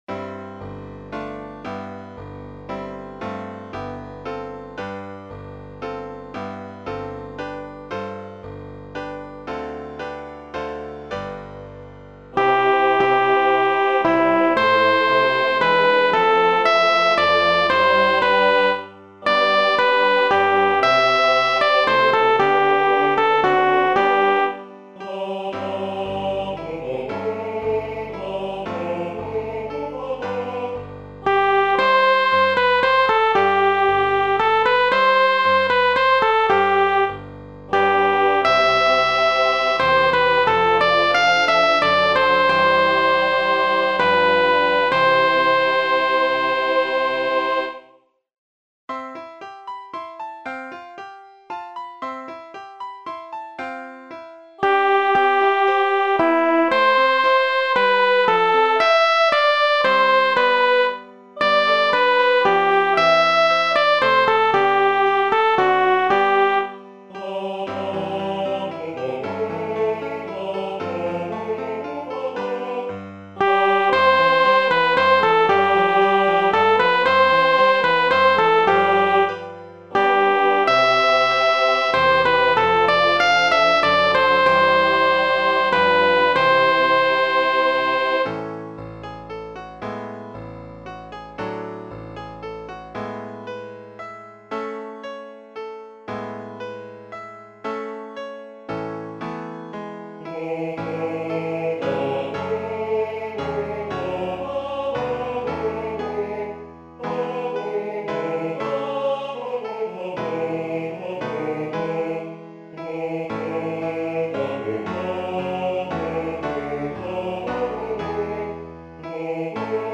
ソプラノ（フレットレスバス音）